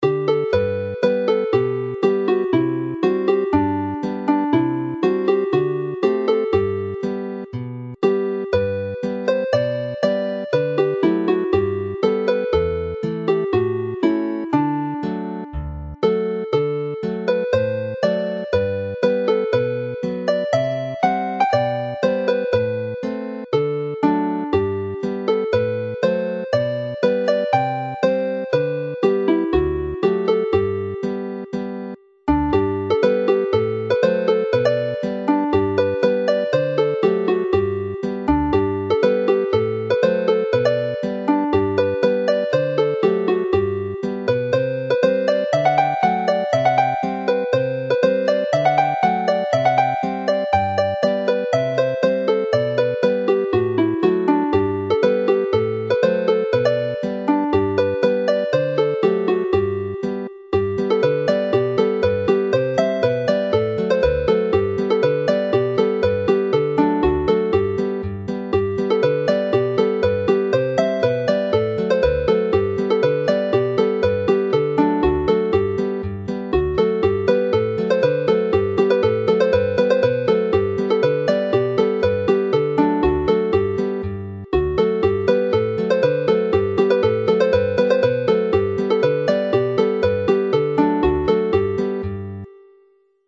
The lyrical Mwynen Merthyr is clearly from the South; Hufen Melyn is a familiar and catchy melody which does not fit into normal dance tune patterns, whilst Gwreiddyn y Pren Ffawydd is a standard 16-bar 2A 2B.